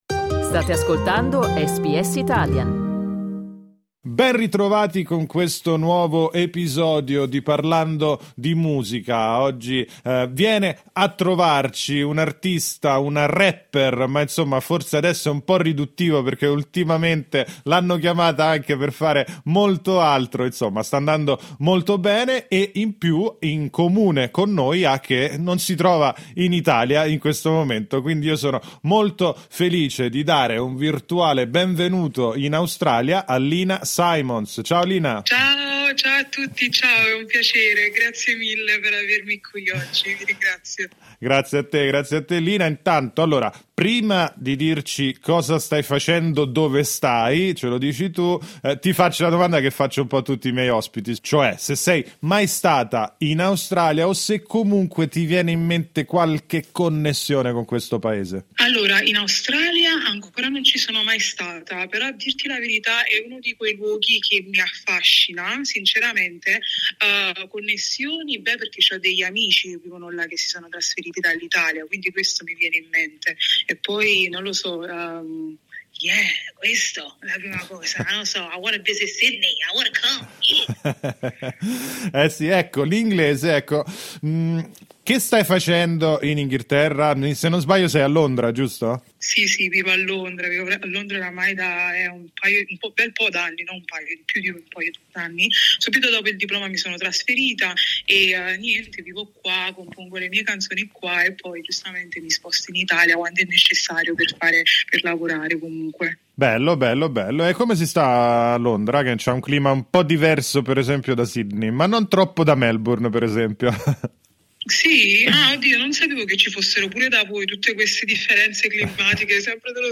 Clicca sul tasto "play" sotto il titolo del podcast per ascoltare l'intervista Ascolta SBS Italian tutti i giorni, dalle 8am alle 10am.